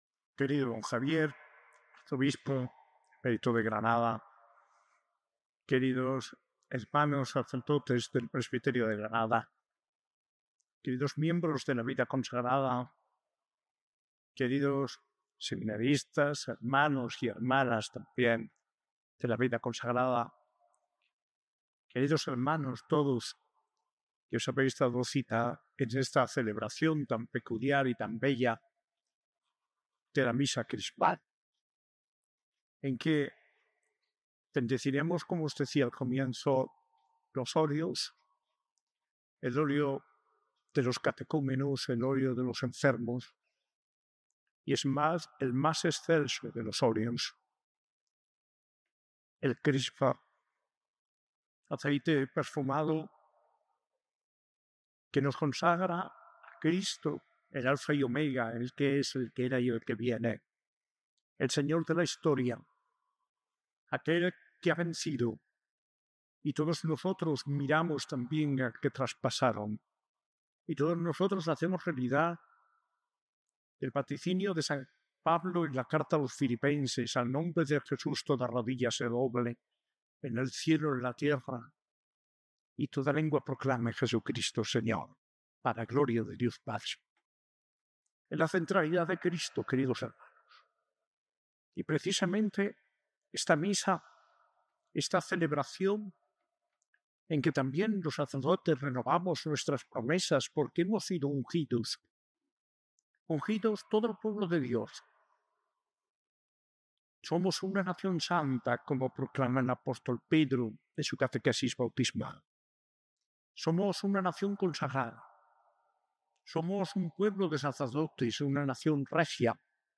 Homilía de Mons. José María Gil Tamayo, arzobispo de Granada, en la Misa Crismal celebrada el Miercoles Santo, el 16 de abril de 2025, con el arzobispo emérito y el clero diocesano, que ha renovado sus promesas sacerdotales.